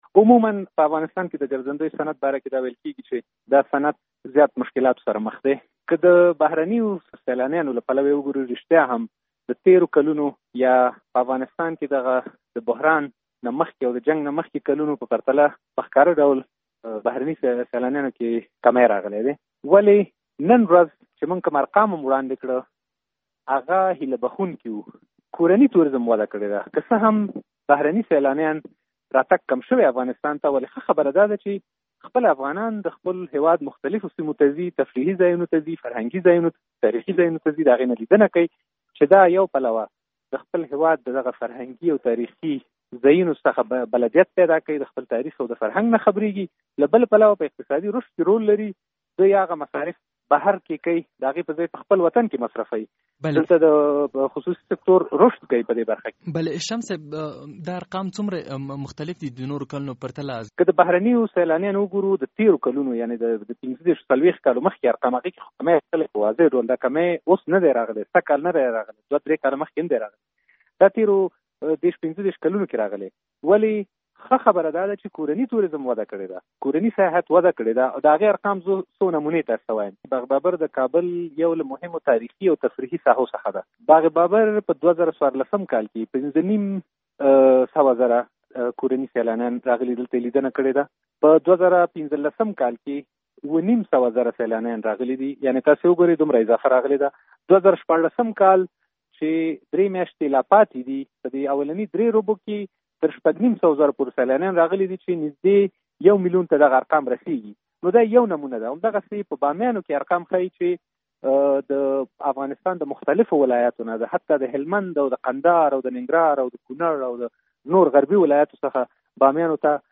له زردشت شمس سره مرکه